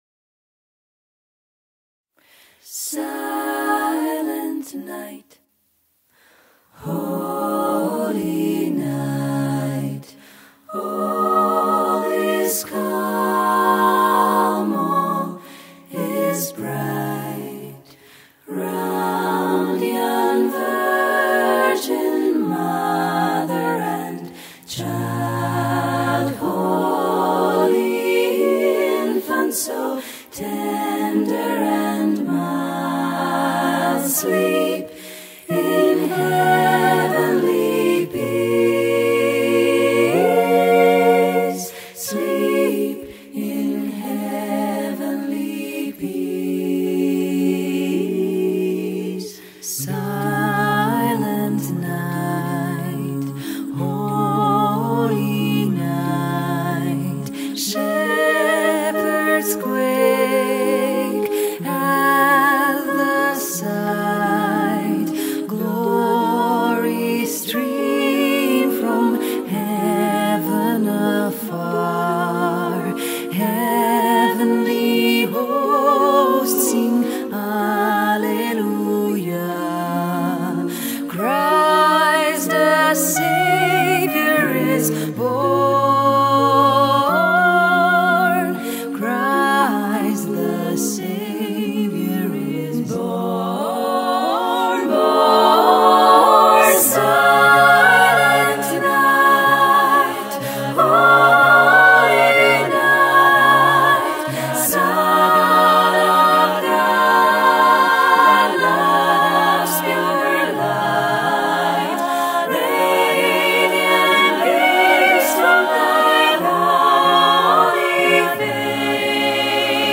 Voicing: SSAATB a cappella